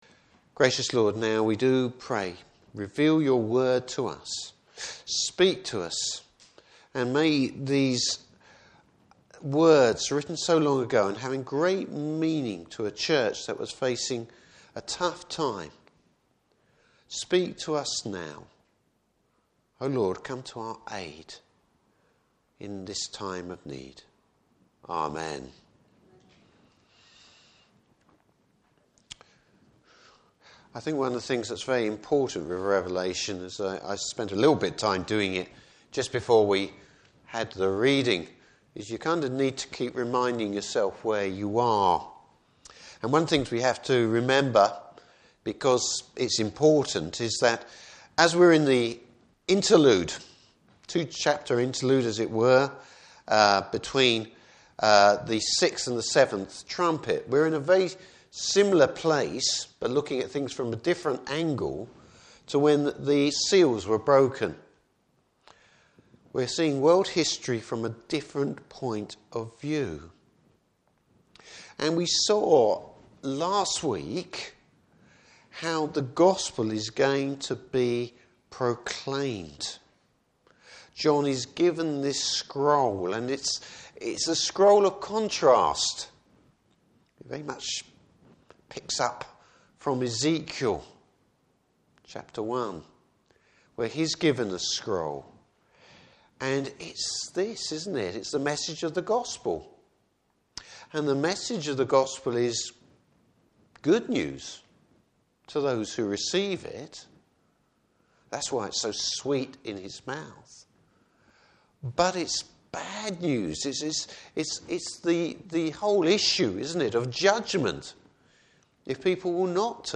Service Type: Evening Service Bible Text: Revelation 11.